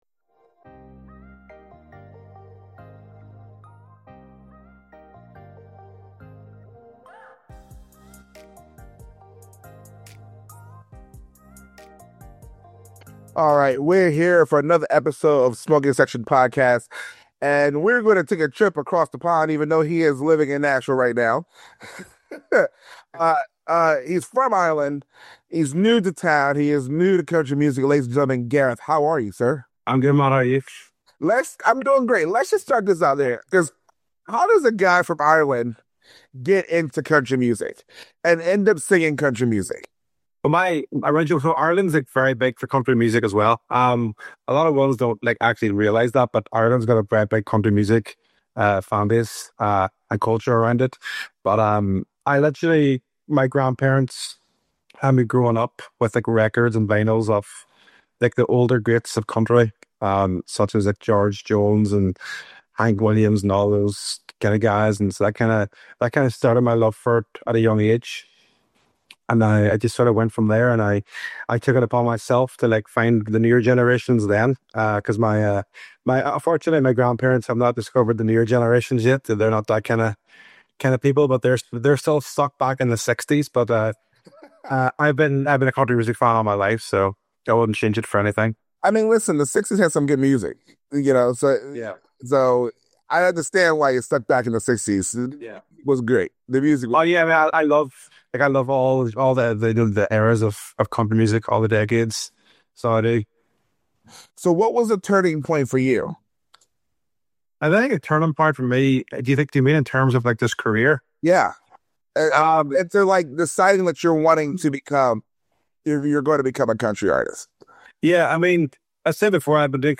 Smoking Section centers around conversations with various music industry professionals, from all avenues, as well as business owners, discussing their roads to success, in hopes of educating and inspiring others to follow in their footsteps -- All while having a cigar and drink.